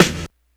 snare03.wav